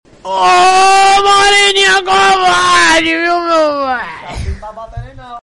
moreninha covarde Meme Sound Effect